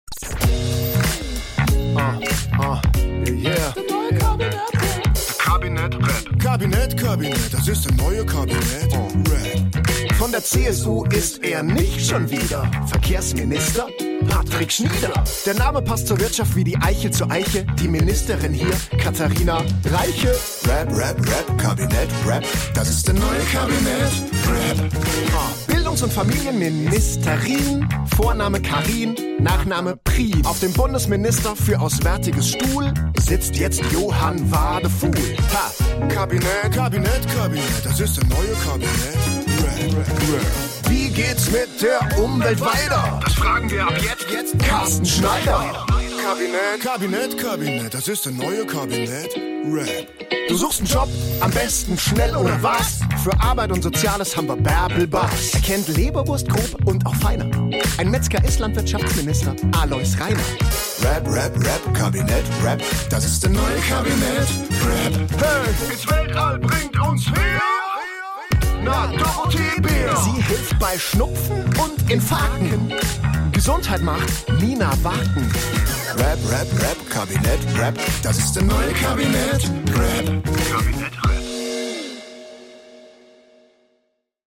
Der Rap zum Erinnern
Das ist der neue Kabinett-Rap!